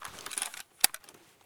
draw.ogg